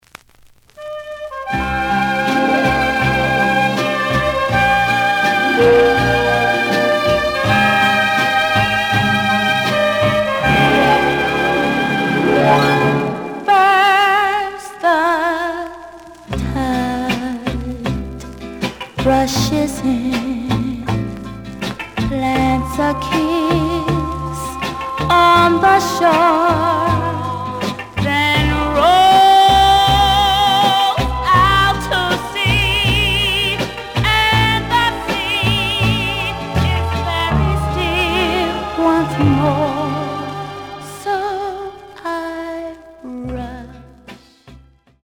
The audio sample is recorded from the actual item.
●Genre: Soul, 60's Soul
Slight click noise on both sides due to a bubble.)